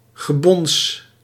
Ääntäminen
IPA : /nɔkiŋ/